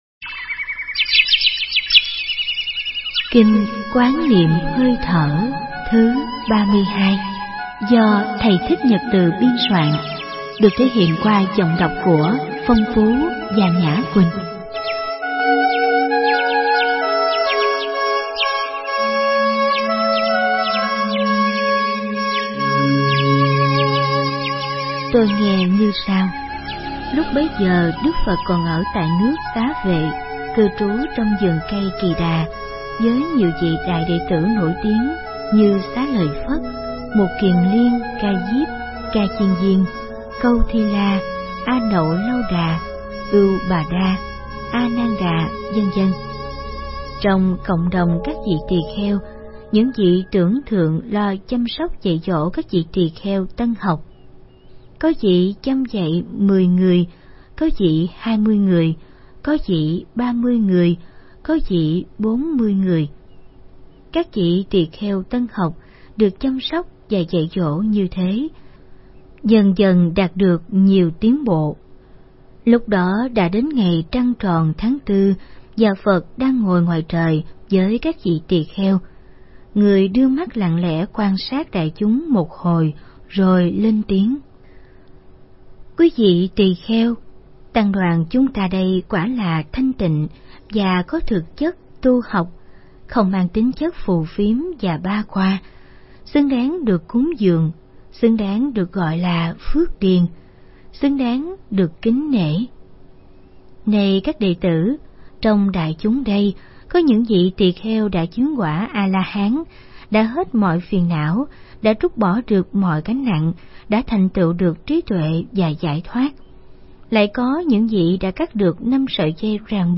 Kinh Tụng Hàng Ngày 32. Kinh Quán Niệm Hơi Thở